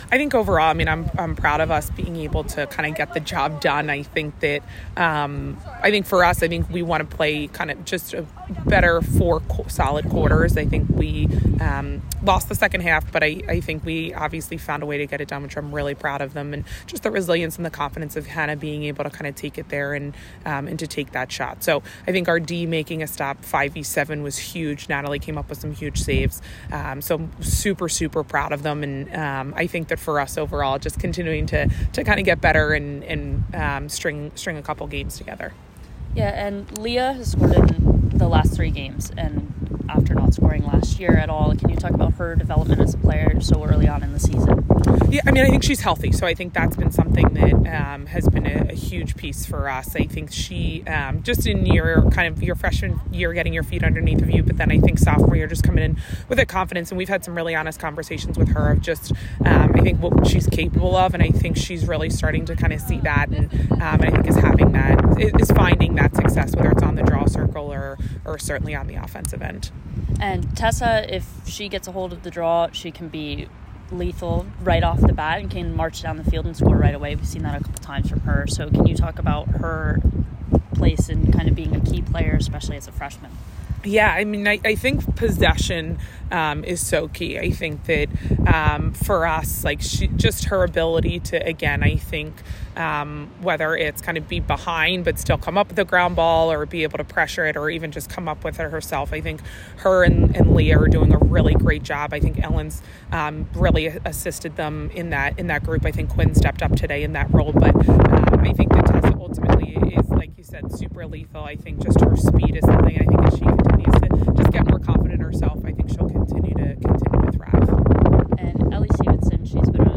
Stonehill Postgame Interview